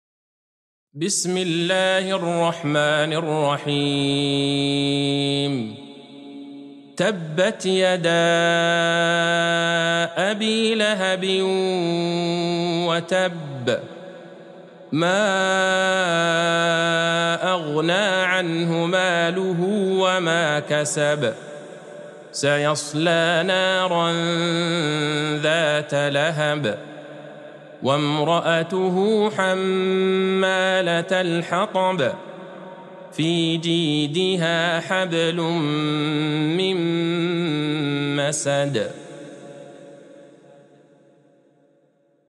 سورة المسد Surat Al-Masad | مصحف المقارئ القرآنية > الختمة المرتلة ( مصحف المقارئ القرآنية) للشيخ عبدالله البعيجان > المصحف - تلاوات الحرمين